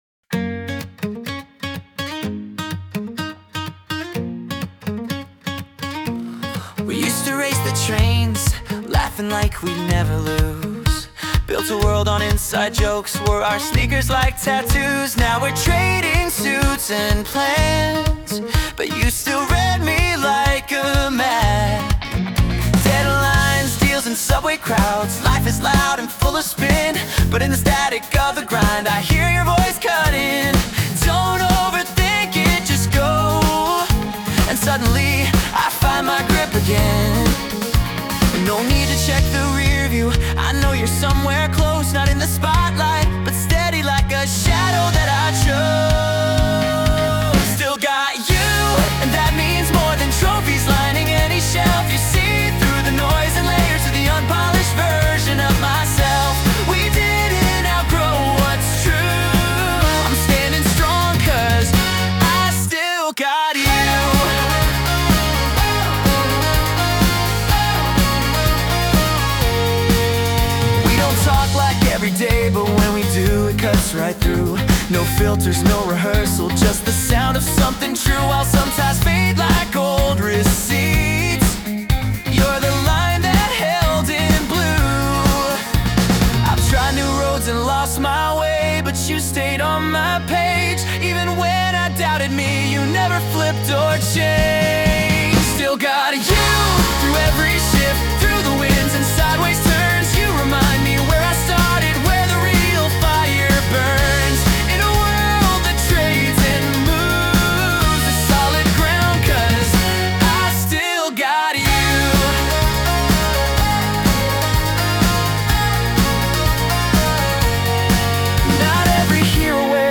著作権フリーオリジナルBGMです。
男性ボーカル（洋楽・英語）曲です。
アップテンポなサウンドに込めました♪♪